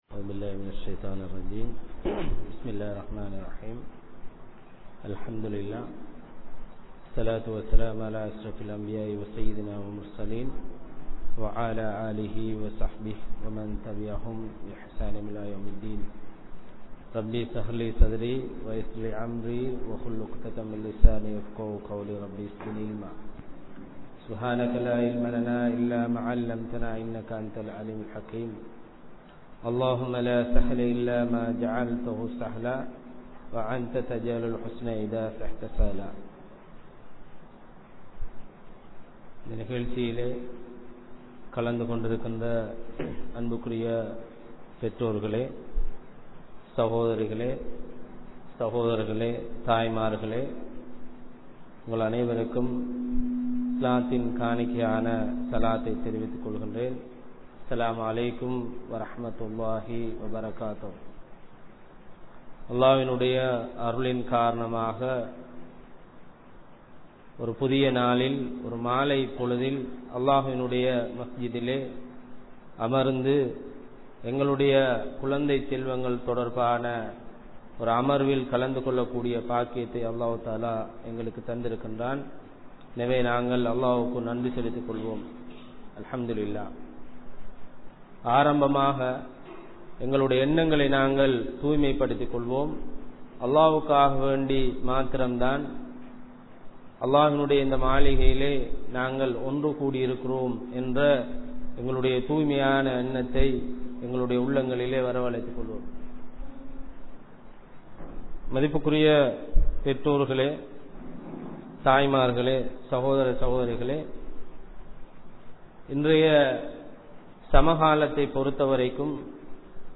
Islamiya Paarvaiel Kulanthai Valarpum Ulaviyalum (இஸ்லாமிய பார்வையில் குழந்தை வளர்ப்பும் உளவியலும்) | Audio Bayans | All Ceylon Muslim Youth Community | Addalaichenai